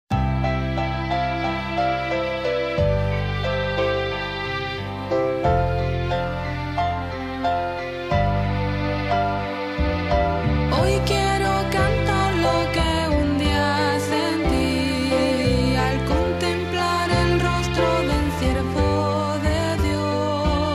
primer CD coral